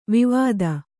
♪ vivāda